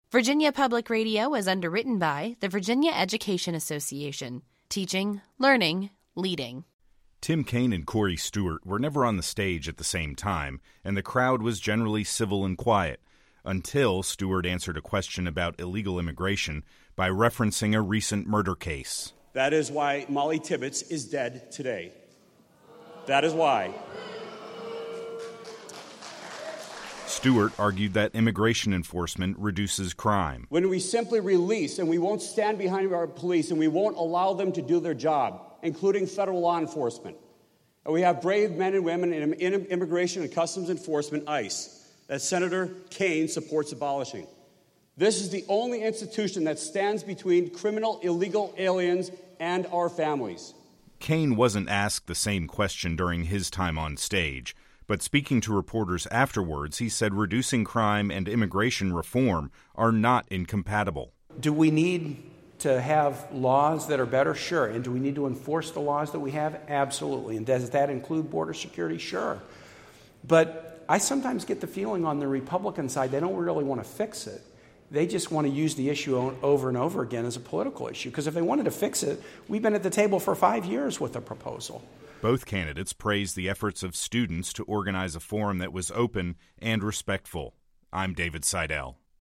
A couple of hundred people attended the candidate forum inside Squires Student Center.